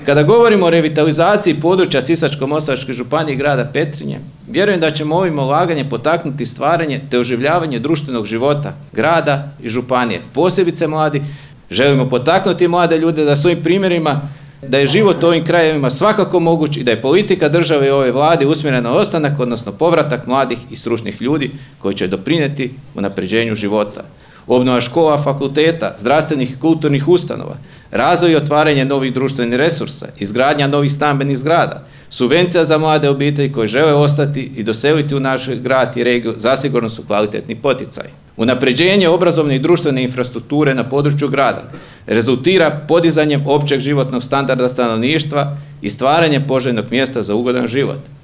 U utorak, 04. ožujka 2025. godine, u Petrinji je svečano otvorena novoobnovljena zgrada Učiteljskog fakulteta – Odsjek u Petrinji.